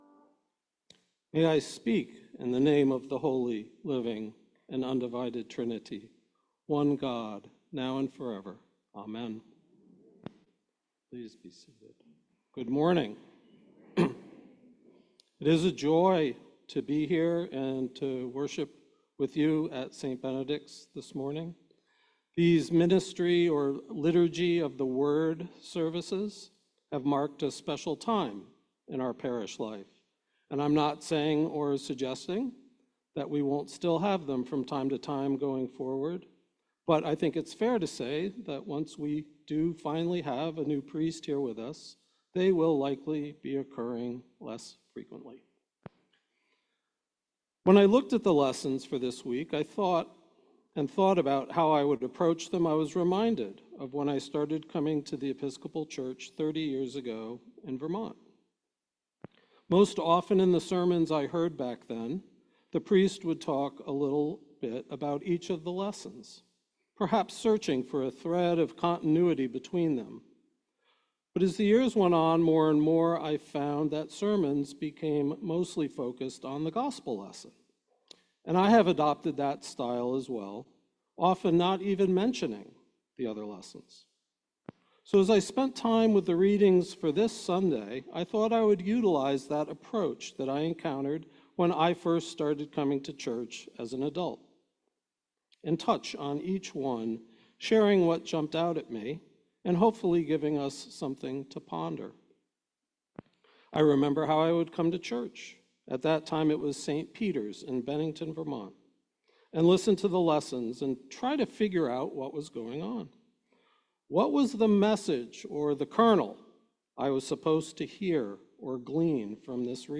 Sermon-August-11th.mp3